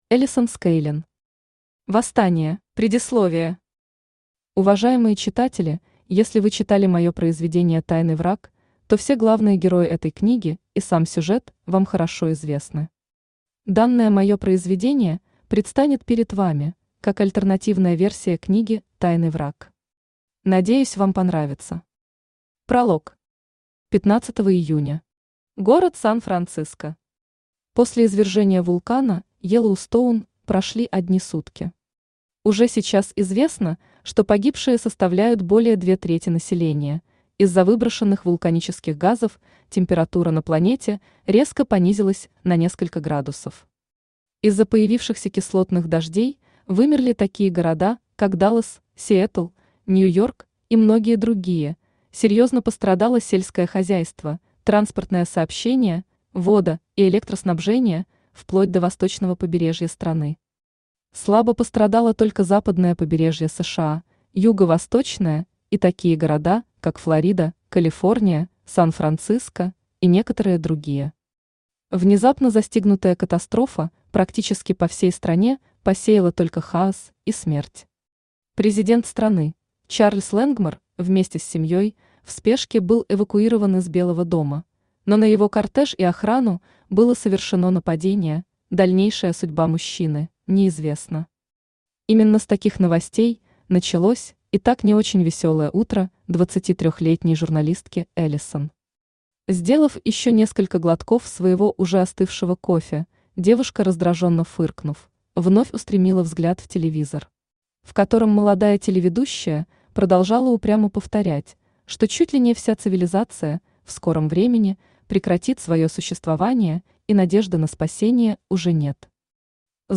Аудиокнига Восстание | Библиотека аудиокниг
Aудиокнига Восстание Автор Alison Skaling Читает аудиокнигу Авточтец ЛитРес.